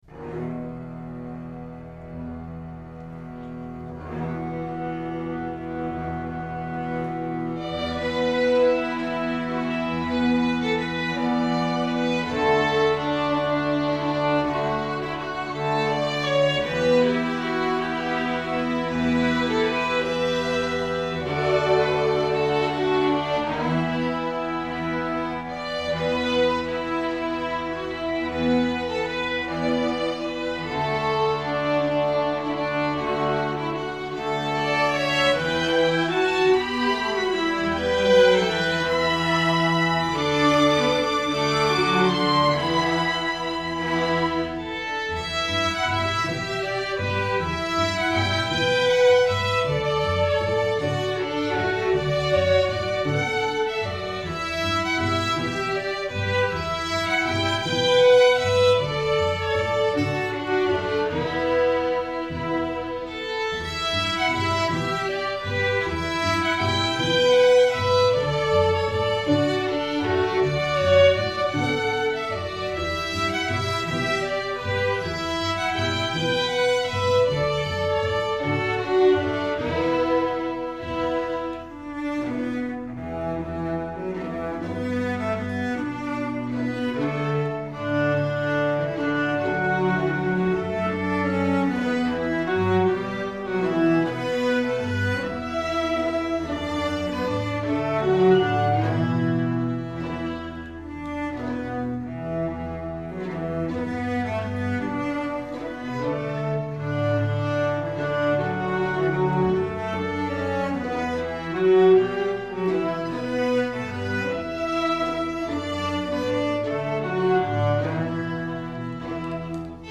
INTERMEDIATE, STRING QUARTET
Notes: double stops, pizz, smear, mordents
Key: E dorian/ D major